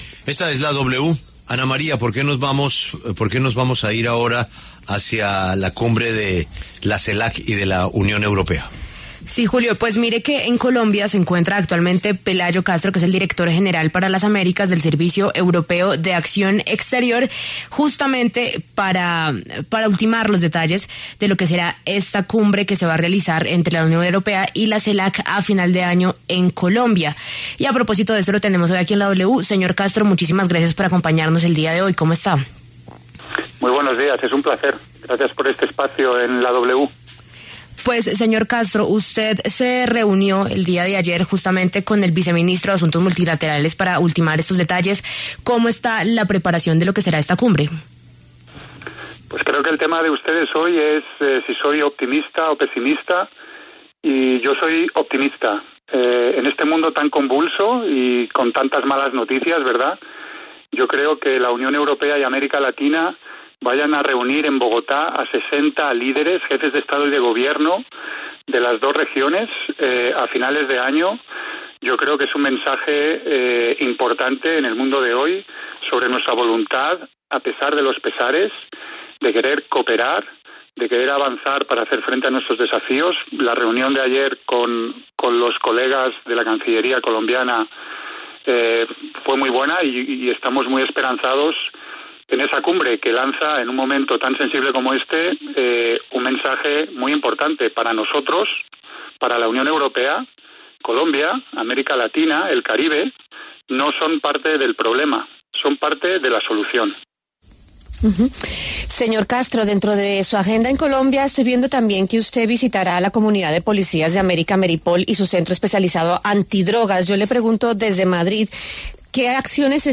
En La W conversamos con Pelayo Castro para conocer detalles sobre esta visita, la preparación de la cumbre y sus perspectivas alrededor de la relación de Colombia con los países europeos.